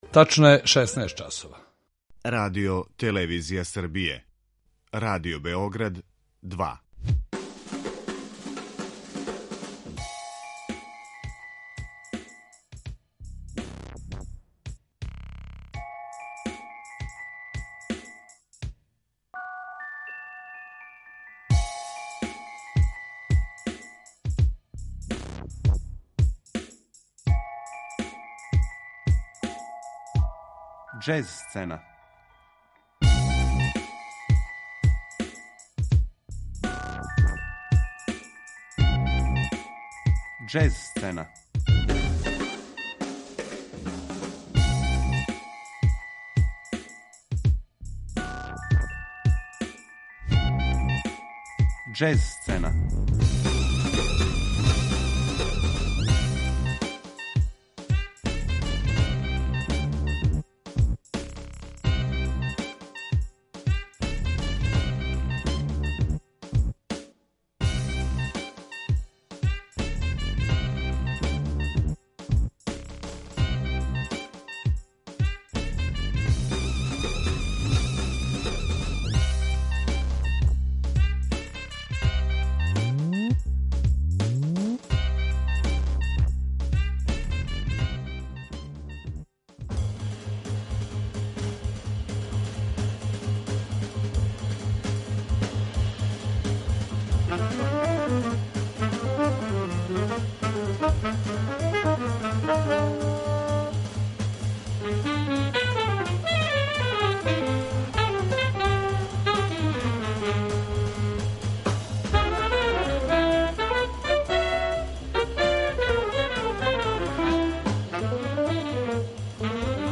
Белгијска џез сцена
dzez.mp3